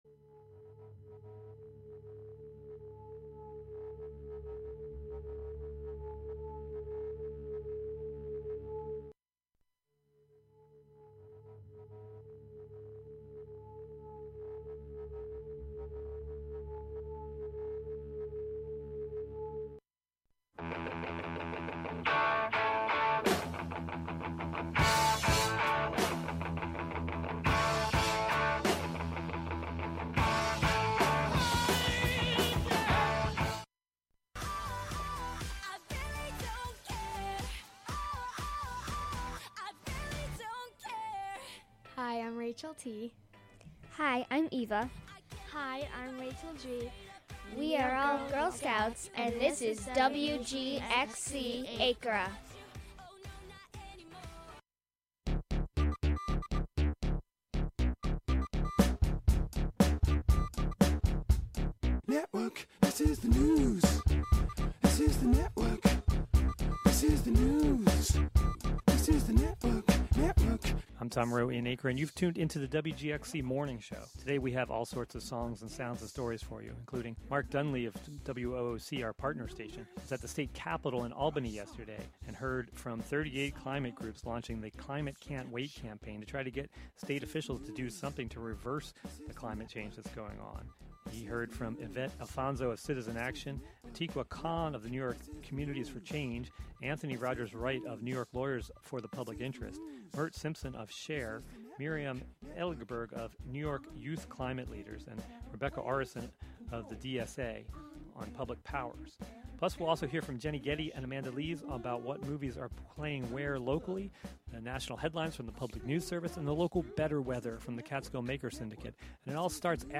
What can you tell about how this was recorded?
Sometimes national, state, or local press conferences, meetings, or events are also broadcast live here.